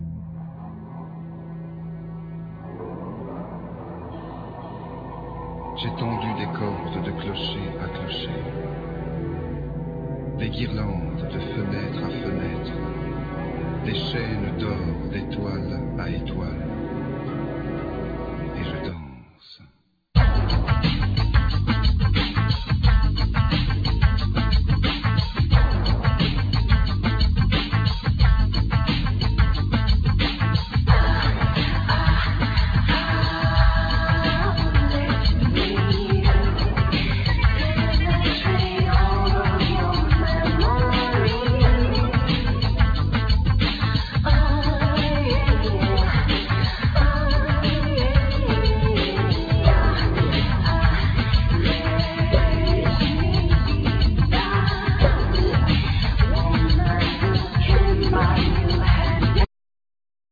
Piano,Electronics
Bass,Effects,Beats
Vocals,Bodhran,Percussions,Darbuka,Tin whistle
Harp,Sensuational backing vocals
Oud